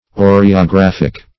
Oreographic \O`re*o*graph"ic\, a.